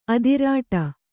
♪ adirāṭa